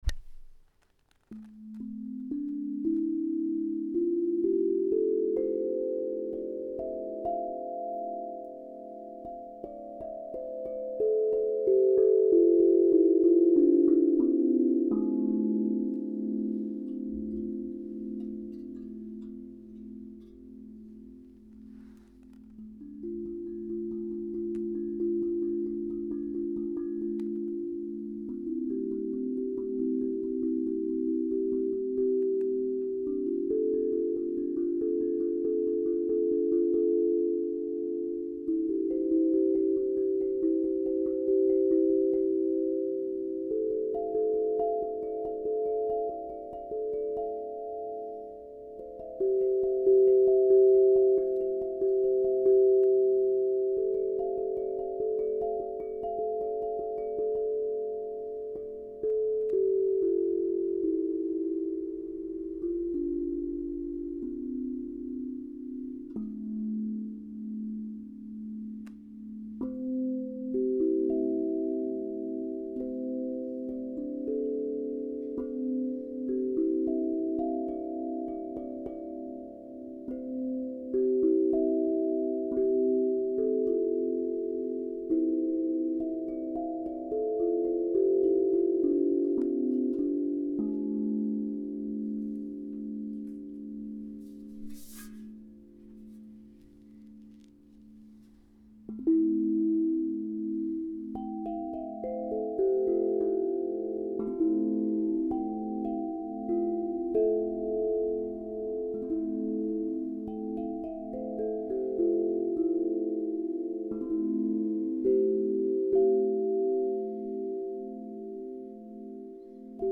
Ce drum allie puissance et douceur, parfait pour des accompagnements de groupe de yoga ou de voyages sonores.
arbre-de-vie-double-la-douceur-432-hz.mp3